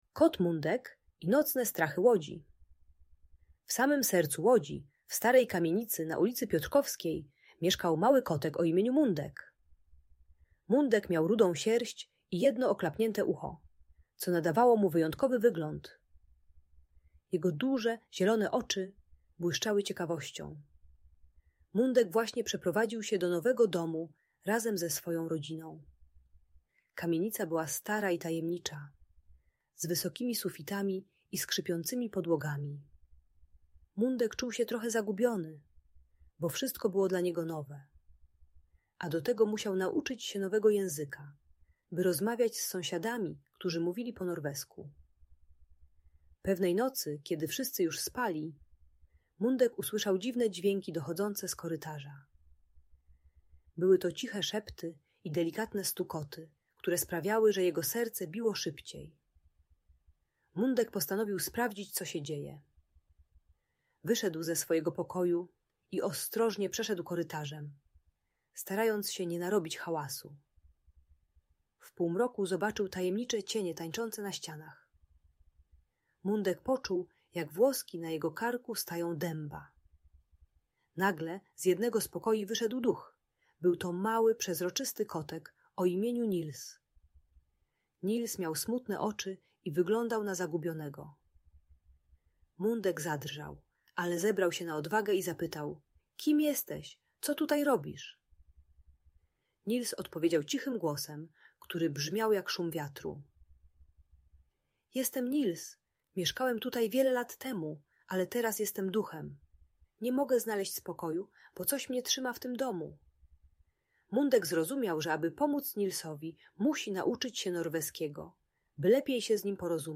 Kot Mundek i Nocne Strachy Łodzi - Niepokojące zachowania | Audiobajka